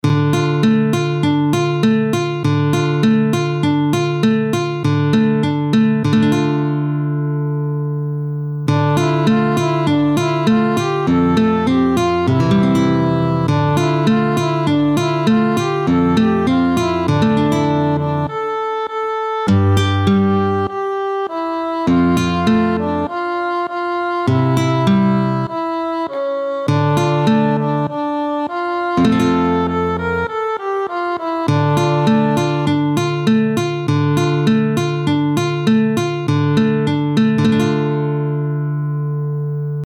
À la faveur des beaux jours, nous revenons sur notre série de comptines et chansons avec une partition pour guitare et voix de « Coccinelle demoiselle »…
À la faveur des beaux jours, nous revenons sur notre série de comptines et chansons avec une partition pour guitare et voix de « Coccinelle demoiselle », mélodie incontournable de la petite enfance.
Comptine-Coccinelle-Demoiselle.mp3